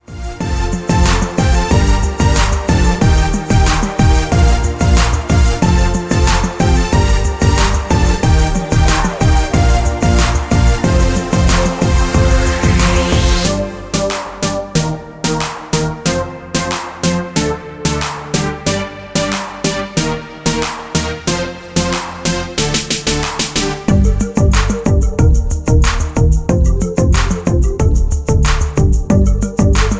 Backing track files: Duets (309)